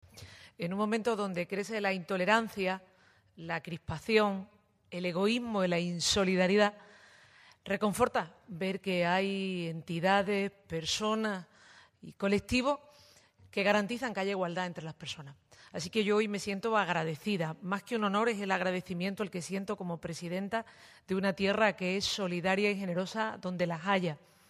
en una tierra tan generosa y solidaria como Andalucía formato MP3 audio(0,19 MB), manifestó la presidenta de la Junta, Susana Díaz, durante la entrega el pasado 25 de septiembre, en Sevilla, de los Premios Solidarios ONCE Andalucía 2017, que este año tienen como eje temático -en cada una de las Comunidades Autónomas- el concepto de la diferencia como valor que enriquece a la sociedad.